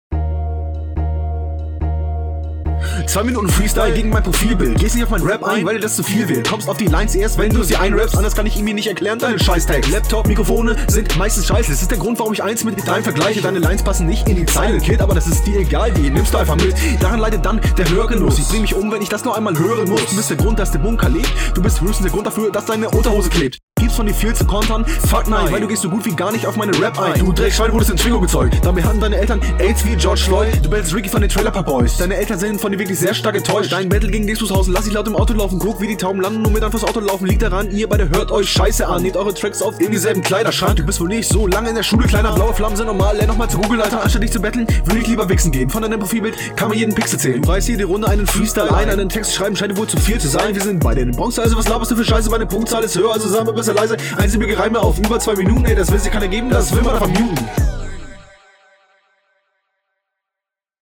Der Anfang ist schon ganz cool geflowt.
Übernimm dich nicht mit den Flows.